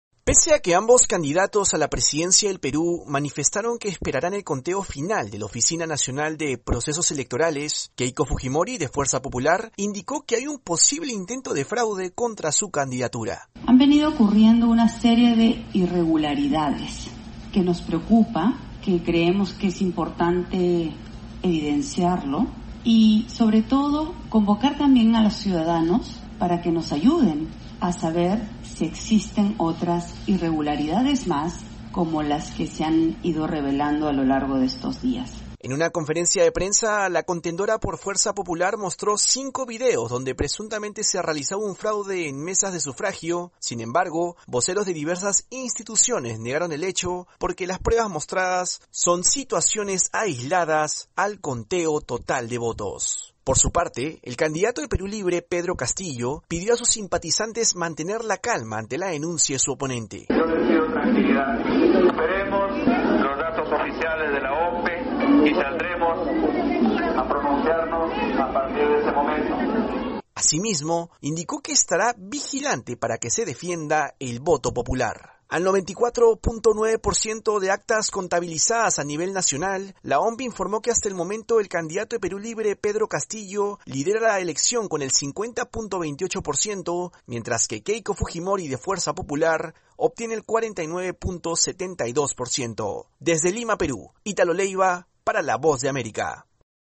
Autoridades electorales en el Perú aún no concluyen el proceso de conteo de votos y los candidatos aguardan definiciones con una diferencia de medio punto porcentual. Informa desde nuestra afiliada RPP de Perú